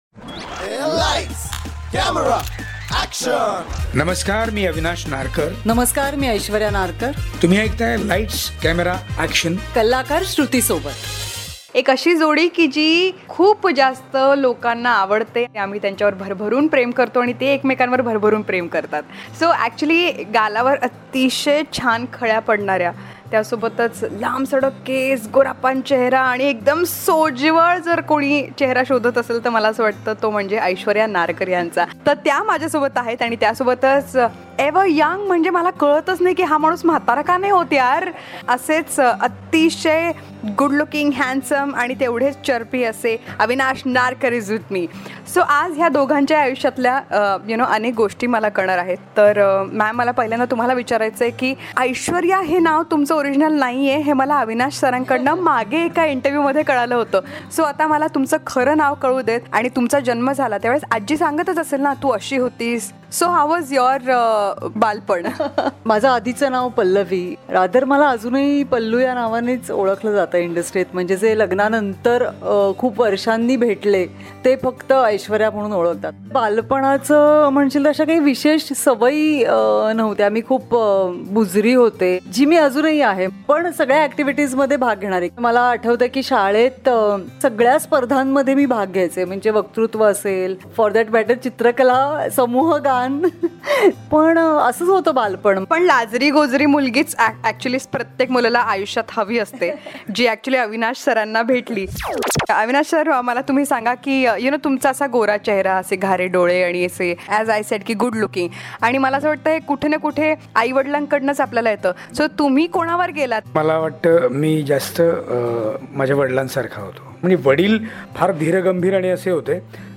Love is a beautiful journey where every step is a destination and every moment eternity .. Listen to this podcast as the cutest romantic couple talks about their journey of life exclusively on Lights Camera Action.